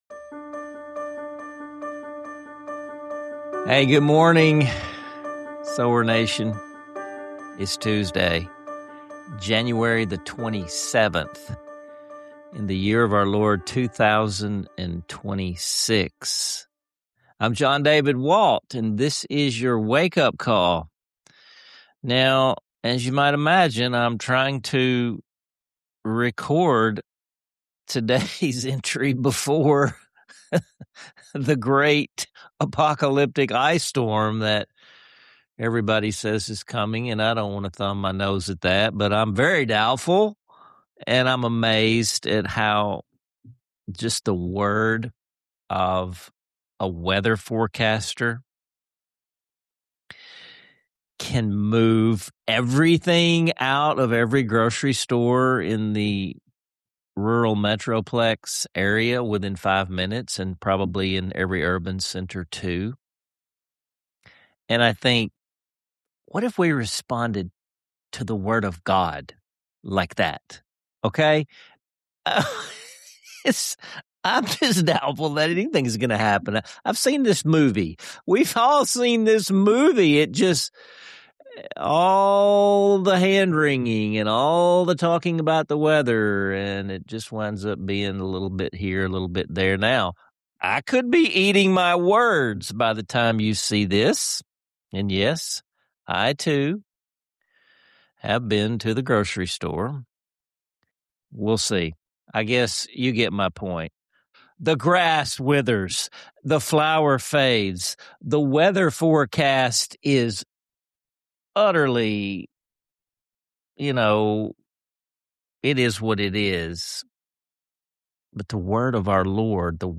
An impromptu, spirit-lifting rendition of “Holy, Holy, Holy” by the farmer preacher band, showing how community can encourage and uplift us in unexpected ways.